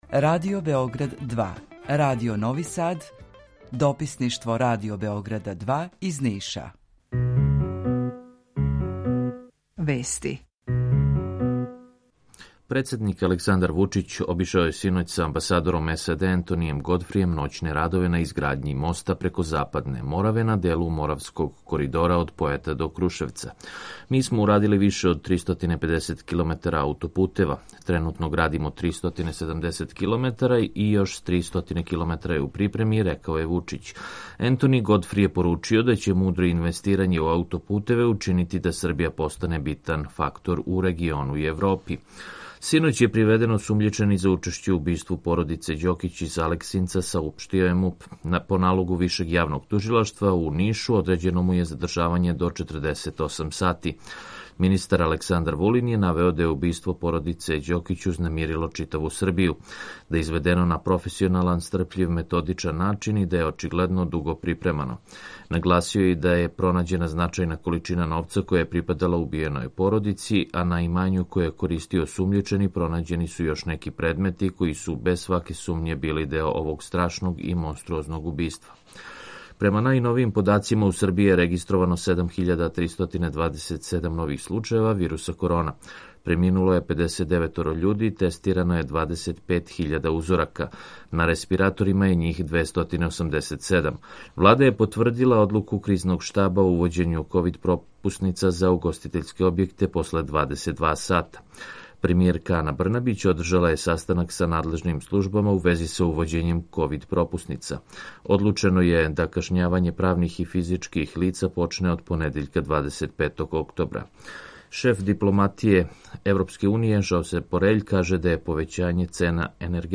Укључење Бањалуке
Јутарњи програм из три студија
У два сата, ту је и добра музика, другачија у односу на остале радио-станице.